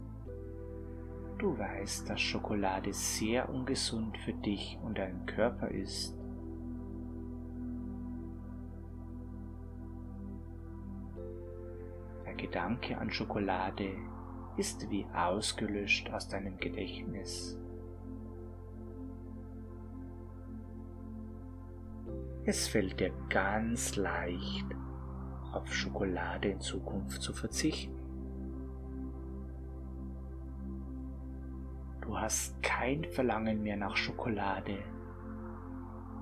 Hörprobe: G2008 – Geführte Hypnose „Ohne Schokolade leben!“